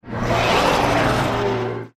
File:Termite queen roar.mp3
Termite_queen_roar.mp3